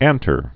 (ăntər)